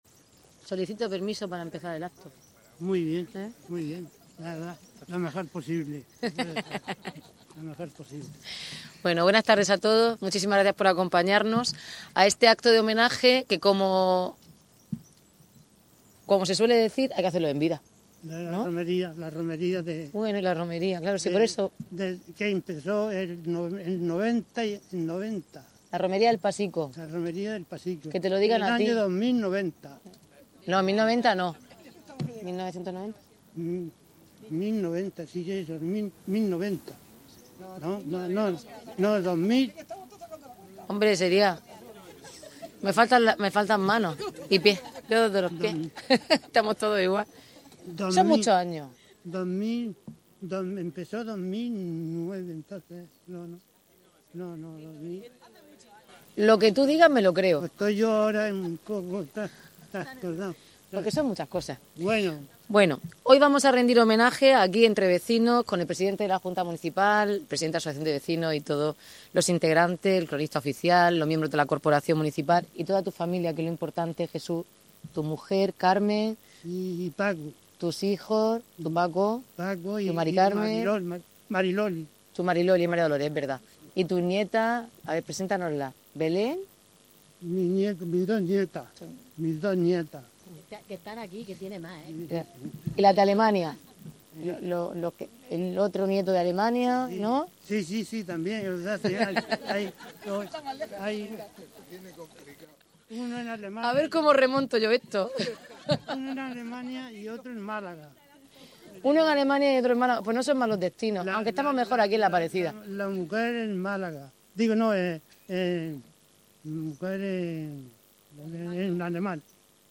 Audio: Declaraciones de la alcaldesa, Noelia Arroyo
La alcaldesa Noelia Arroyo ha descubierto este 17 de diciembre el hito junto al homenajeado, sus allegados y familiares, así como representantes vecinales e integrantes de la Corporación municipal.